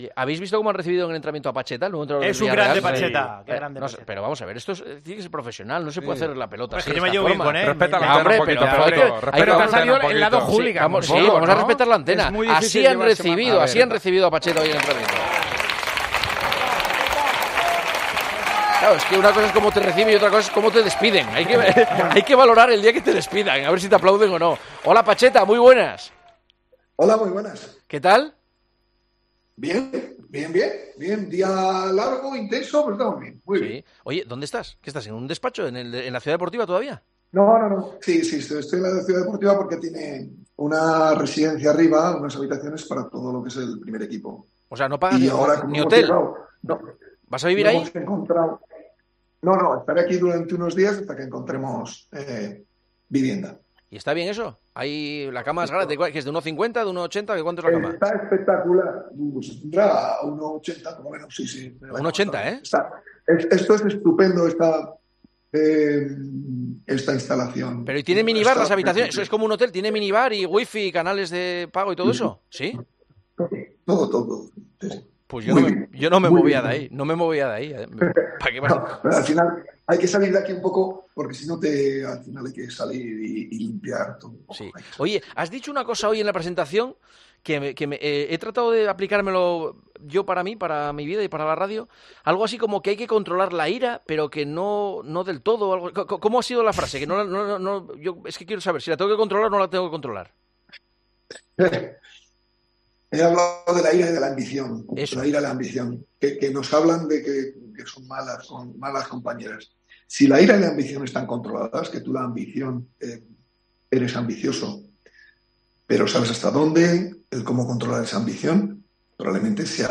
AUDIO: Hablamos con el nuevo entrenador del Villarreal el día en que se ha presentado, sustituyendo a Quique Setién y después de que haya sonado Raúl González...